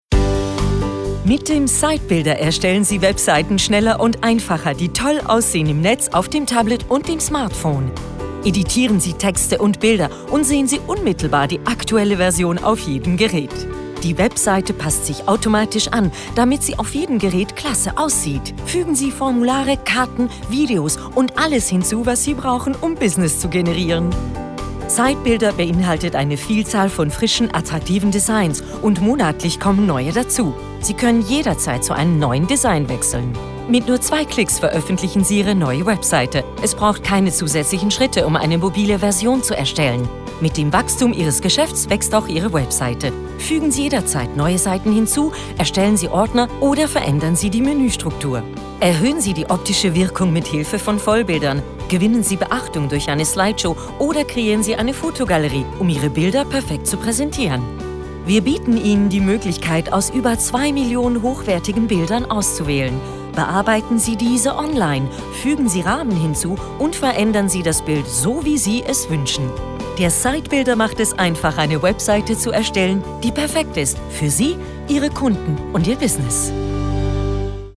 Jetzt da ich den coolen Spot vom Englischen ins Deutsche übersetzen und dann Zeit codiert so sprechen durfte, dass der schon bestehende Film inkl. Musik dem Original aus USA möglichst nah kommt in der Teaser Stimmung und – das ist ja das Wichtigste – weiss ich definitiv wie es geht: zumindest in der Theorie!